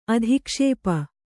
♪ adhikṣēpa